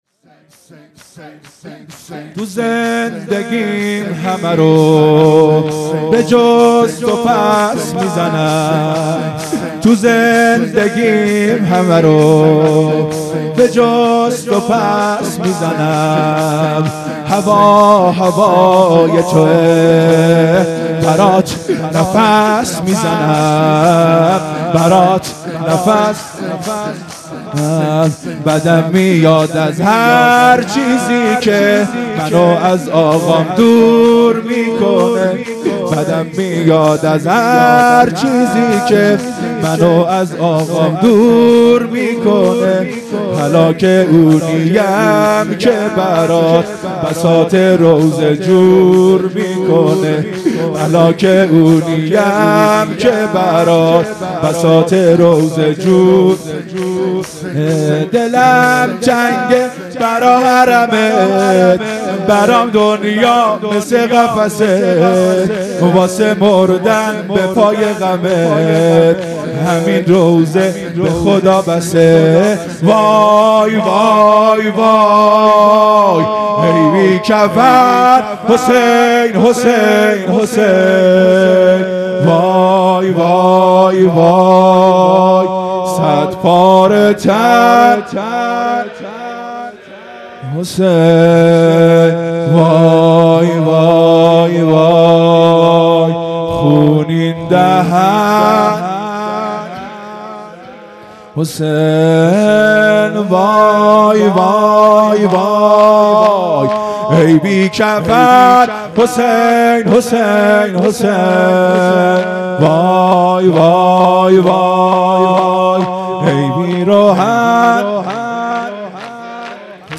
هیئت مکتب الزهرا(س)دارالعباده یزد
مراسم عزاداری محرم الحرام ۱۴۴۳_شب یازدهم